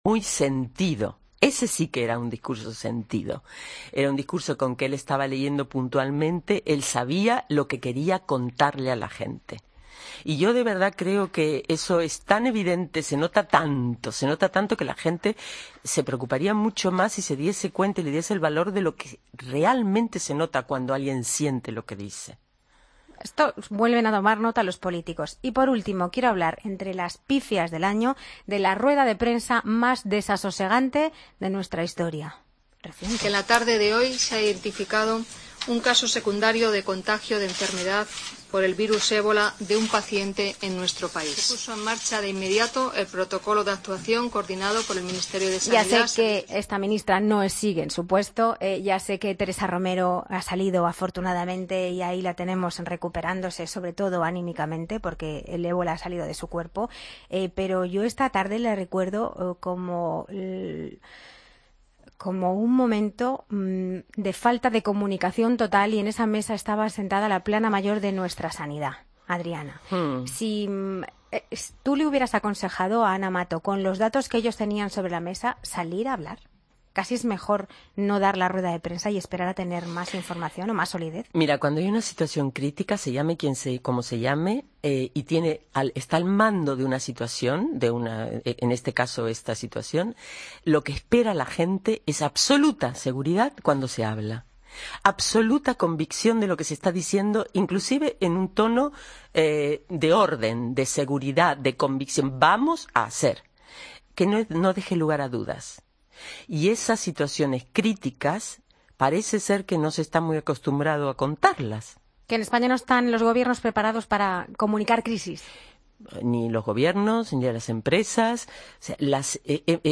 AUDIO: Chema Alonso, experto en ciberseguridad, explica cómo serán las contraseñas del futuro en La Mañana